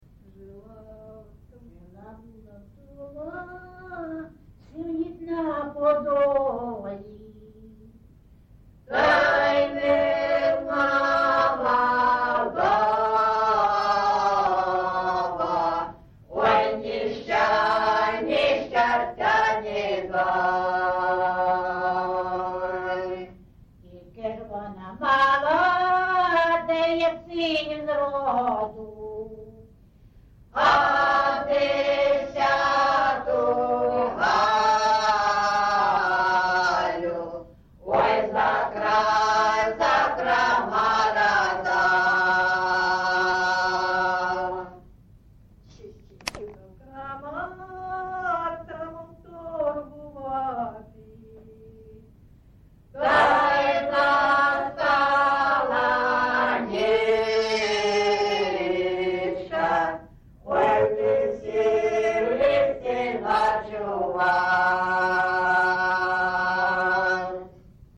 ЖанрПісні з особистого та родинного життя, Балади
Місце записус. Андріївка, Великоновосілківський район, Донецька обл., Україна, Слобожанщина